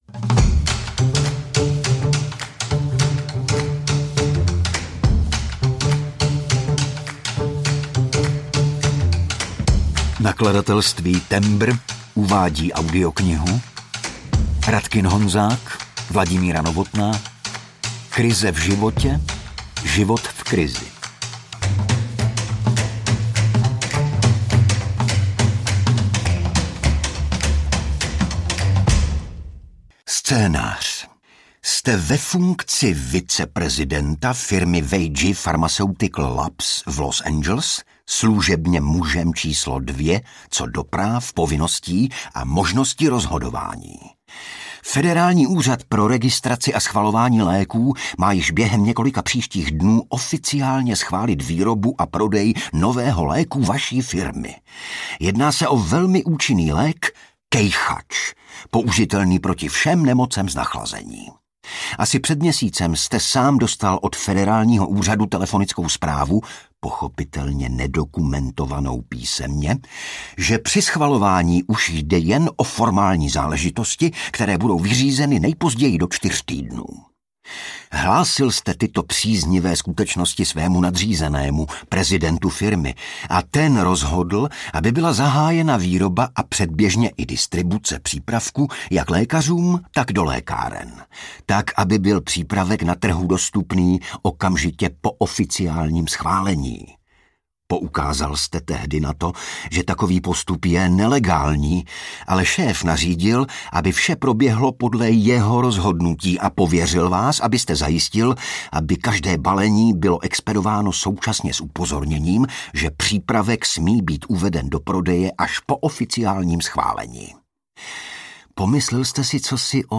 Krize v životě, život v krizi audiokniha
Ukázka z knihy
• InterpretIgor Bareš